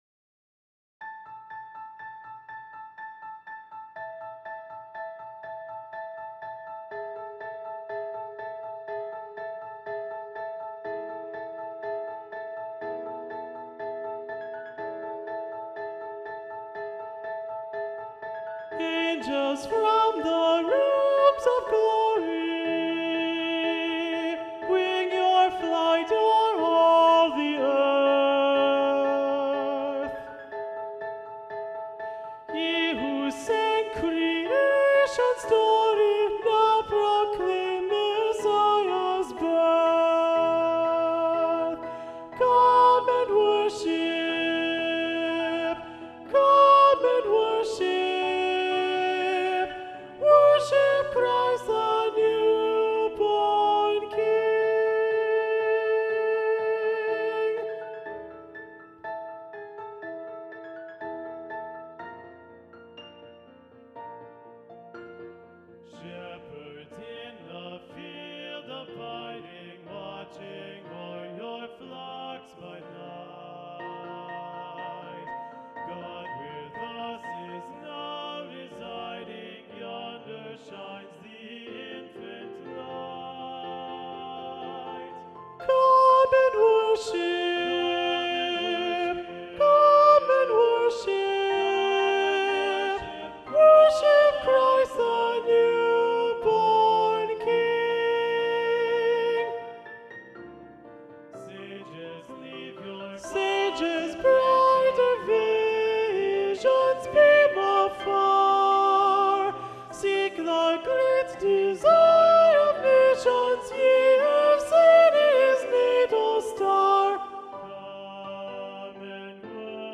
Soprano 1
AngelsFromTheRealmsOfGlorySoprano1.mp3